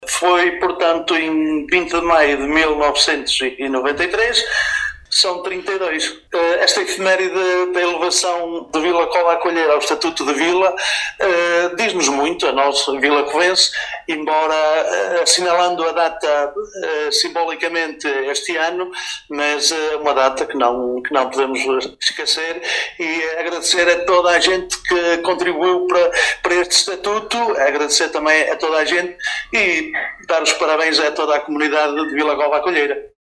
Fernando Guedes, Presidente da Junta de Freguesia, em declarações à Alive FM, fala desta efeméride e da importância da mesma para toda a comunidade Vilacovense.